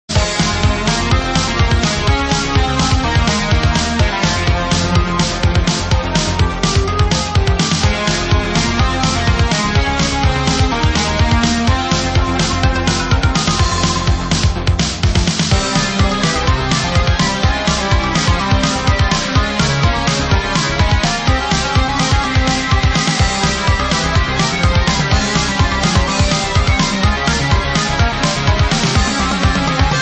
[Lo-Fi preview] Remixers Website